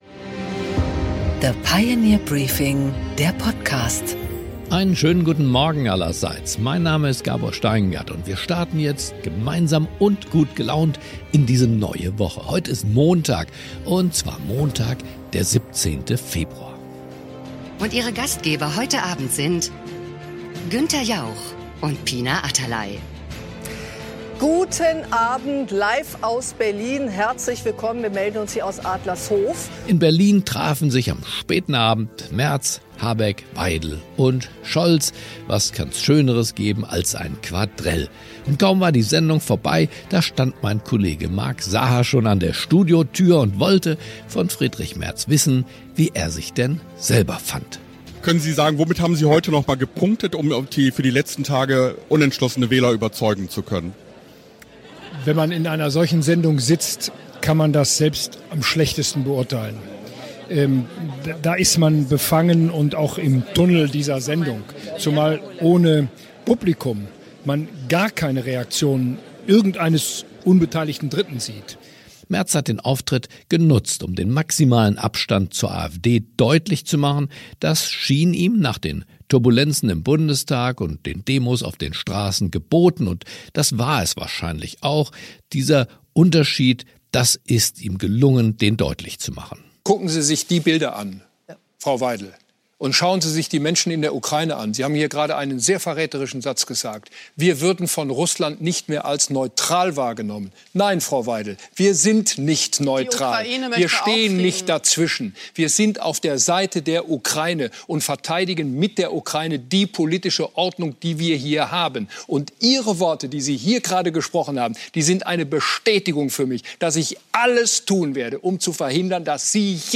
Gabor Steingart präsentiert das Pioneer Briefing
Im Interview: Jens Spahn, MdB (CDU), spricht mit Gabor Steingart über den Auftritt des US-Vizepräsidenten JD Vance bei der Münchener Sicherheitskonferenz und den aktuellen Zustand der transatlantischen Partnerschaft.